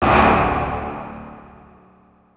TM-88 Hit #03.wav